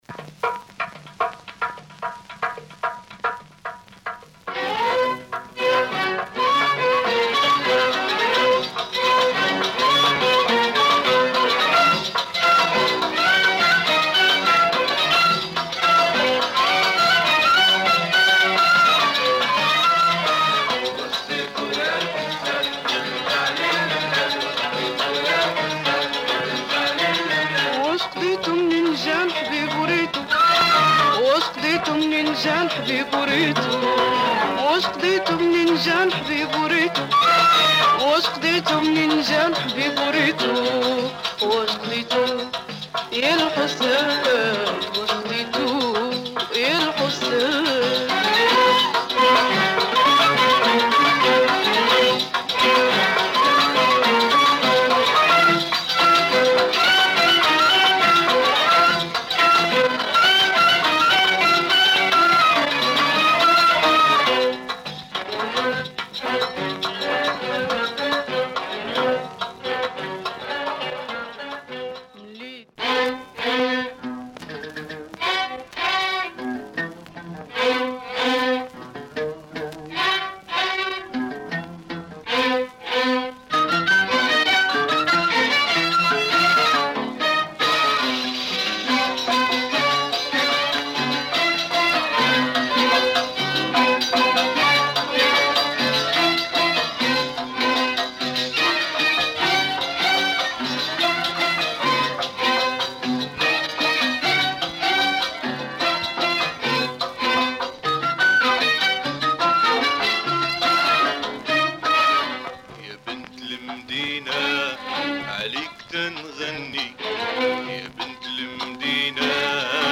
Very rare Moroccan Mizrahi